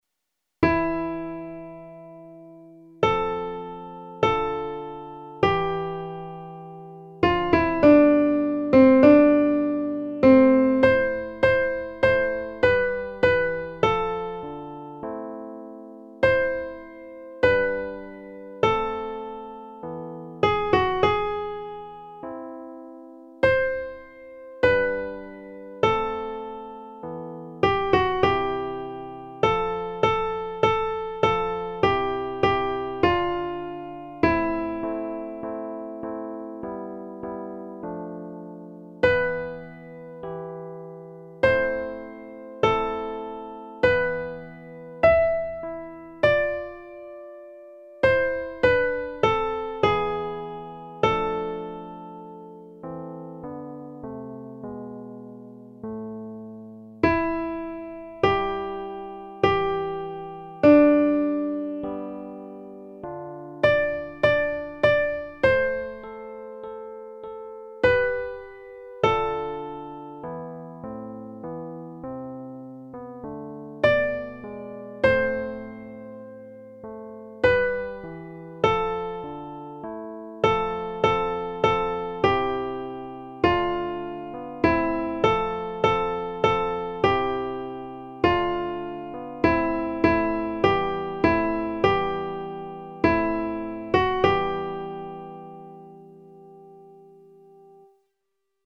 Audios para estudio (MP3)
Soprano